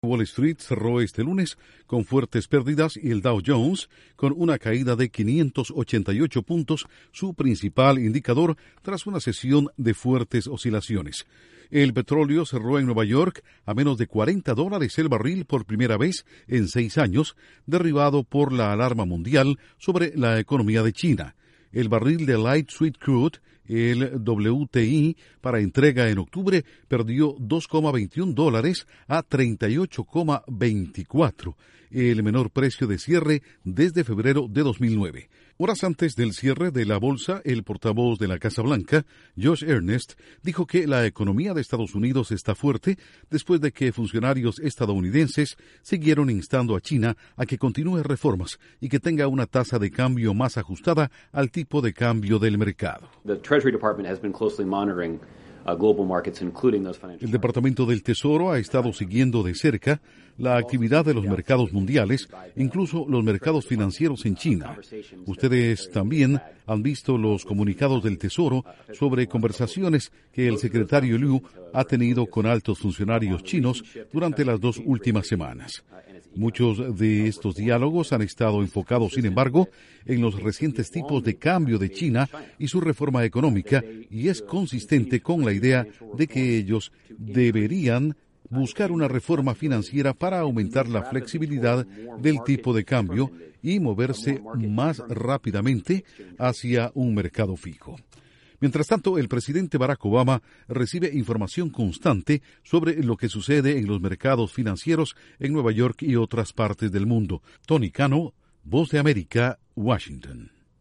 Wall Street cierra este lunes con fuertes pérdidas. La Casa Blanca dice que la economía de Estados Unidos sigue fuerte. Informa desde la Voz de América en Washington